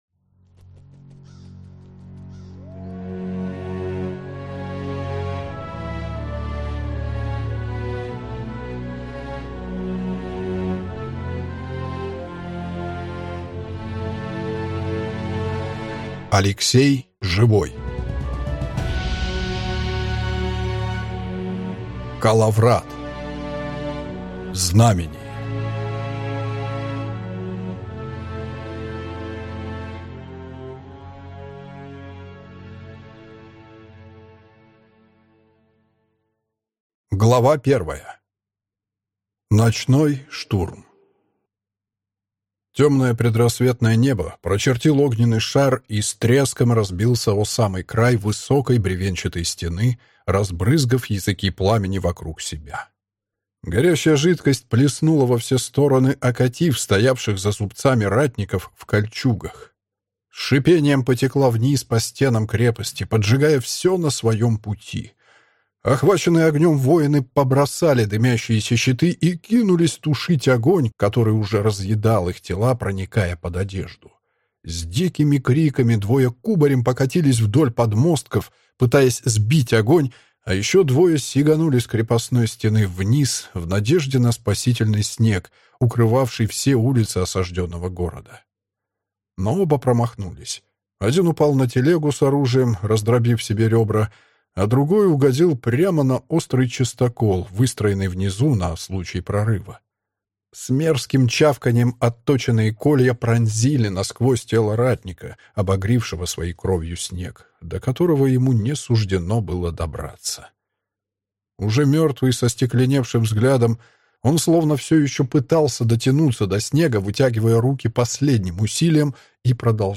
Aудиокнига Коловрат: Знамение. Вторжение. Судьба